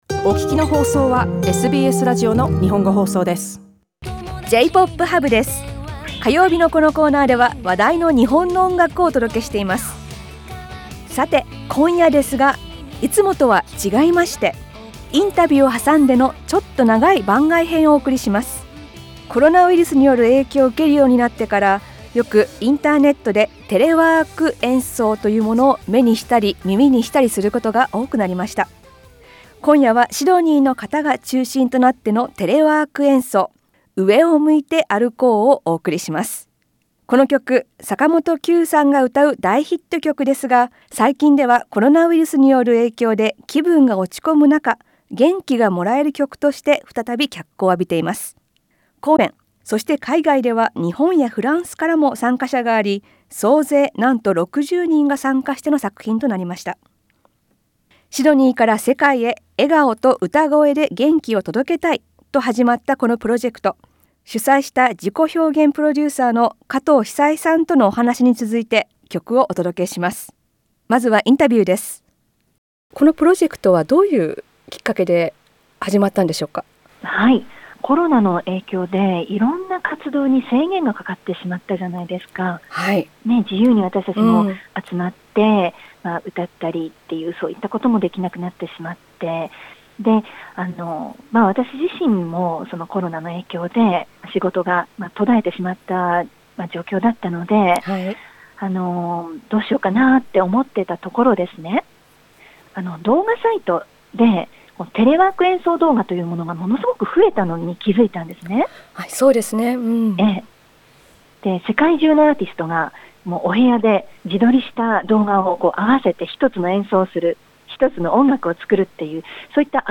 The interview was broadcast as part of regular radio music segment called J-POP HUB .